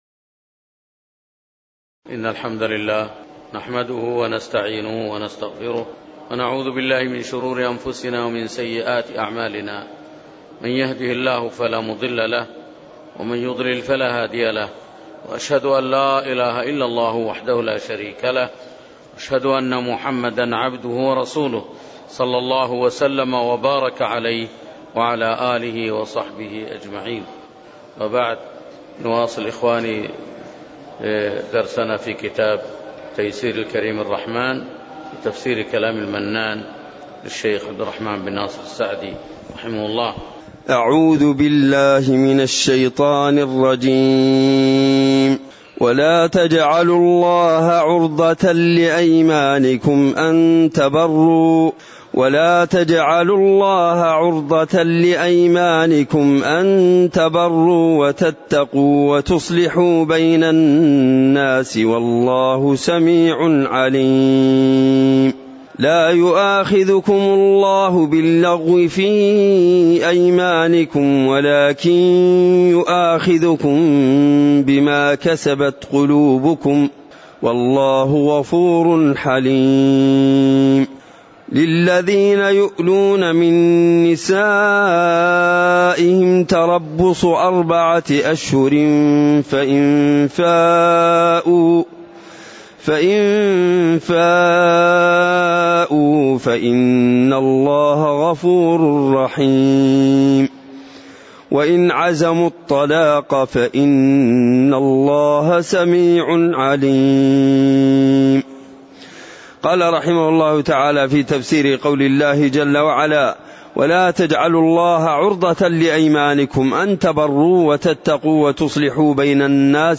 تاريخ النشر ١٥ صفر ١٤٣٩ هـ المكان: المسجد النبوي الشيخ